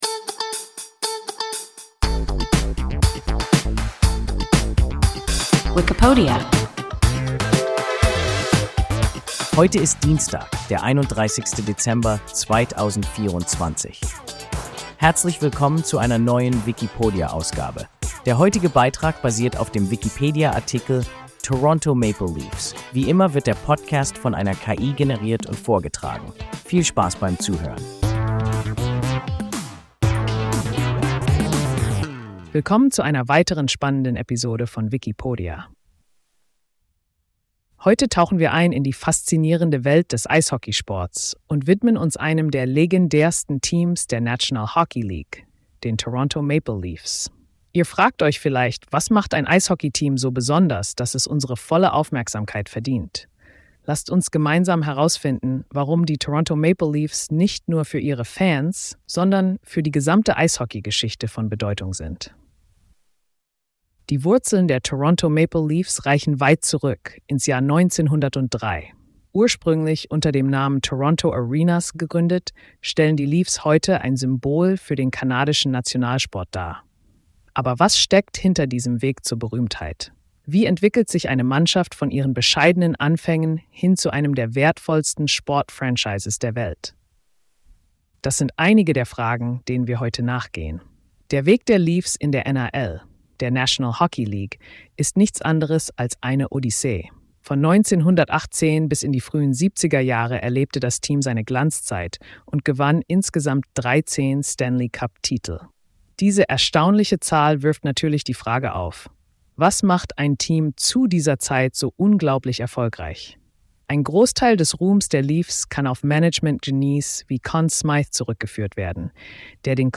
Toronto Maple Leafs – WIKIPODIA – ein KI Podcast